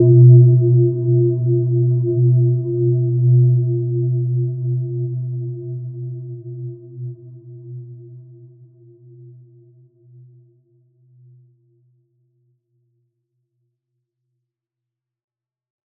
Gentle-Metallic-4-B2-f.wav